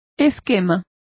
Complete with pronunciation of the translation of skeleton.